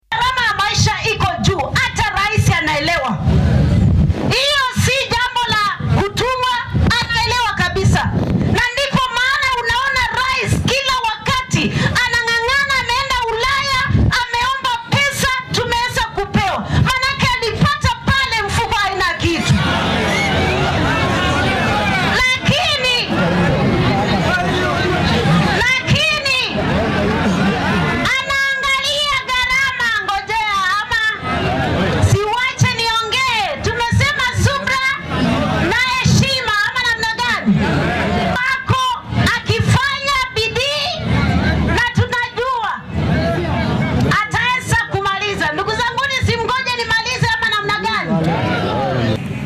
Wasiiradda wasaaradda jaaliyadda bariga Afrika ee EAC, deegaannada oomanaha ee ASALs iyo horumarinta gobollada ee dalka Peninah Malonza ayaa waxaa ku buuqay ama ku ooriyay dad ka qayb galayay munaasabad aas ah oo ka dhacaysay Bariga ismaamulka Kitui. Tani ayaa timid ka dib markii ay sheegtay in madaxweynaha dalka William Ruto uu fahansan yahay sicir bararka nololeed ee jira oo uu caqabaddan la tacaalaya.